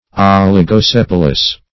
Search Result for " oligosepalous" : The Collaborative International Dictionary of English v.0.48: Oligosepalous \Ol`i*go*sep"al*ous\, a. [Oligo- + sepal.]
oligosepalous.mp3